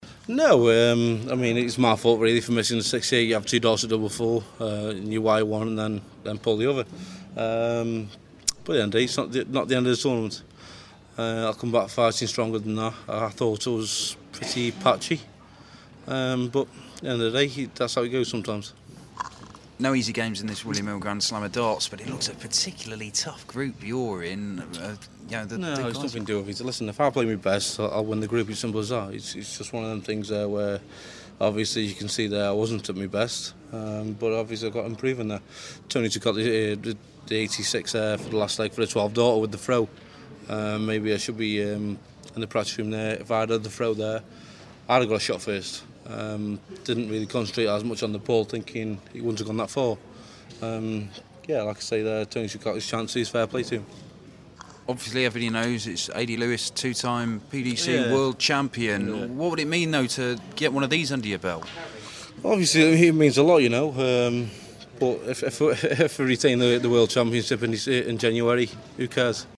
William Hill GSOD - Lewis Interview
World champion Adrian Lewis speaking after a 5-4 defeat to BDO star Tony O'Shea.